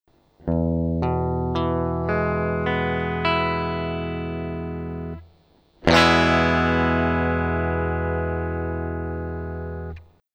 Un accord c’est lorsque plusieurs notes sont jouées simultanément.
Il faut bien laisser résonner les cordes
Ecouter le E